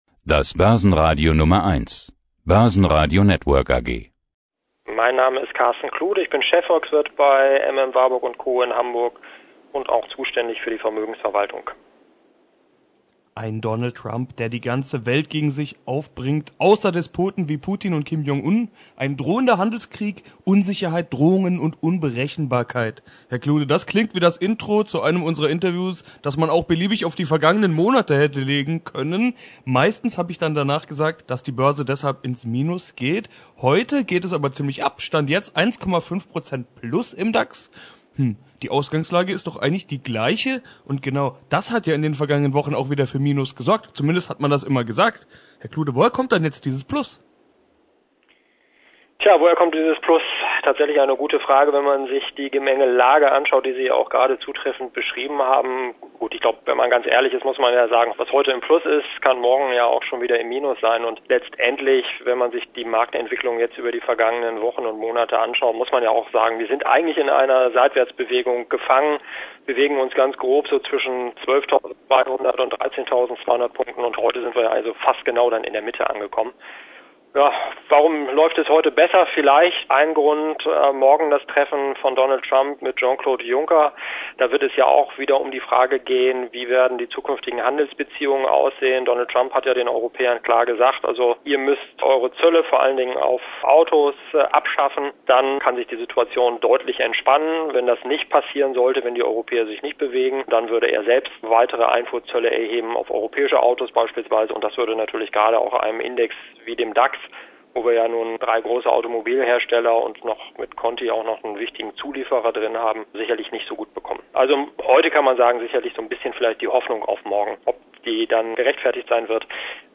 Weitere Informationen Zum Interview